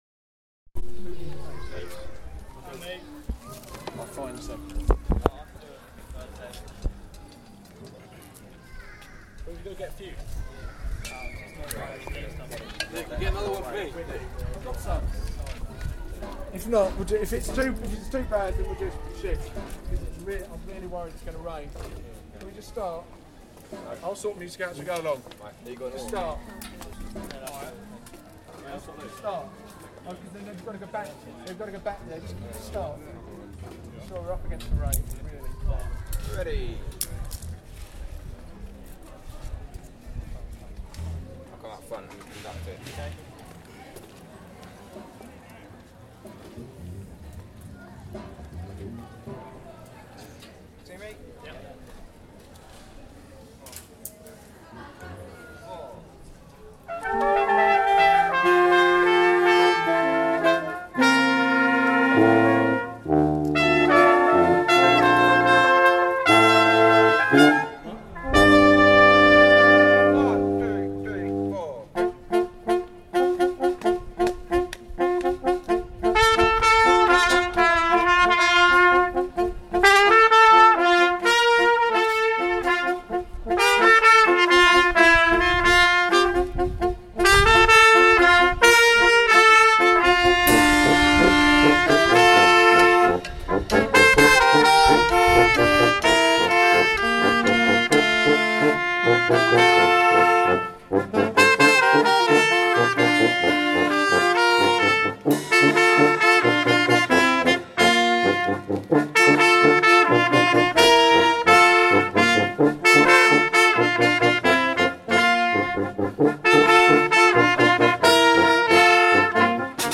Brass